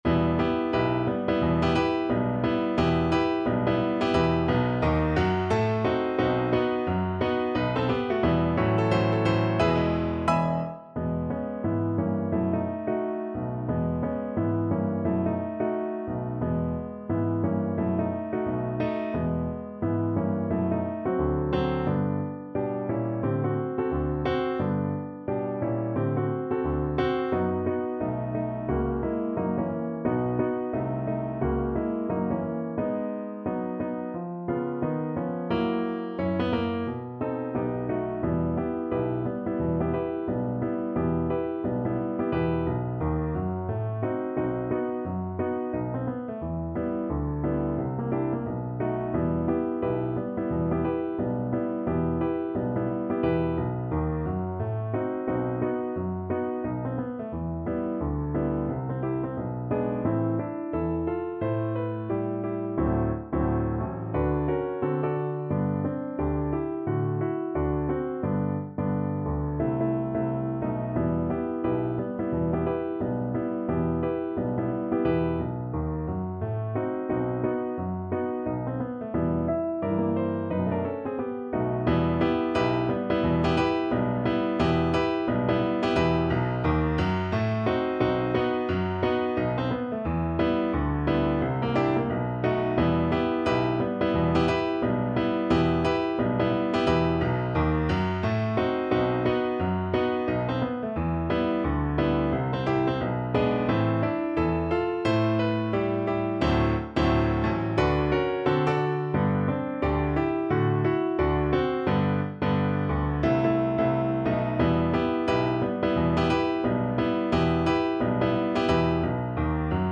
~ = 176 Moderato
Jazz (View more Jazz Cello Music)